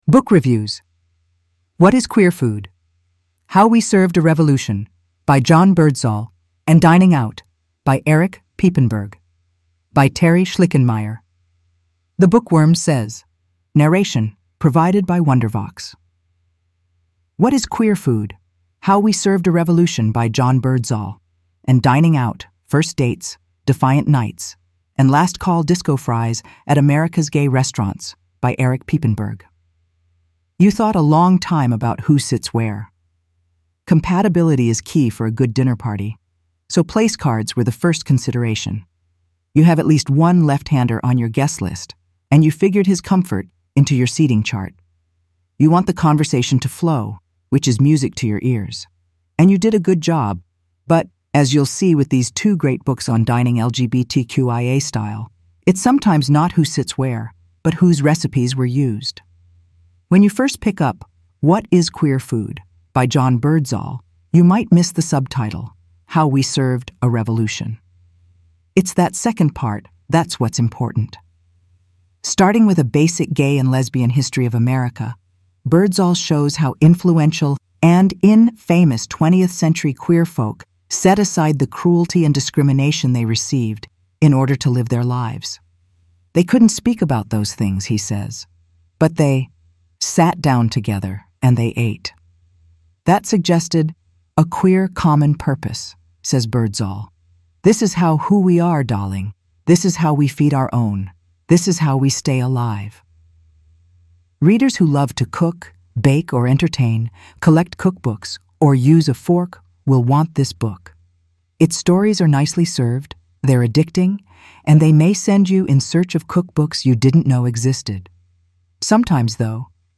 Narration provided by Wondervox.